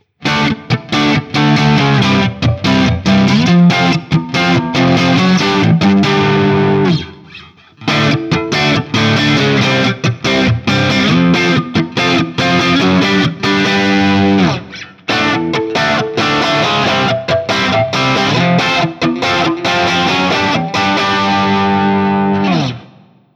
JCM-800
A Barre Chords
For each recording I cycle through the neck pickup, both pickups, and finally the bridge pickup.
I should start by saying that I’m not completely happy with the recordings, specifically on the JCM800 patch.